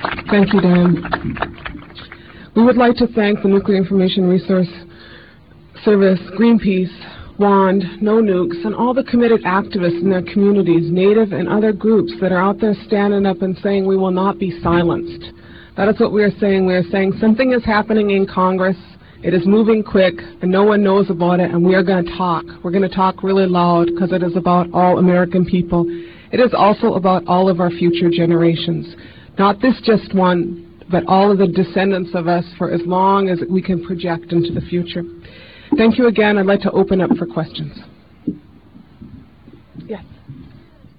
lifeblood: bootlegs: 1997-09-24: honor the earth press conference - washington, d.c.
12. press conference - winona laduke (0:41)